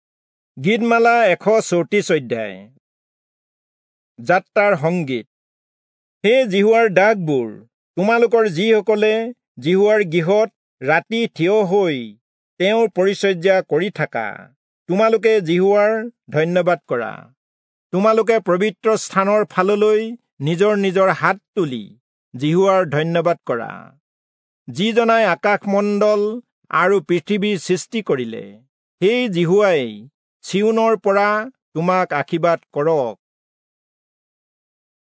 Assamese Audio Bible - Psalms 125 in Rv bible version